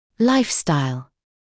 [‘laɪfstaɪl][‘лайфстайл]образ жизни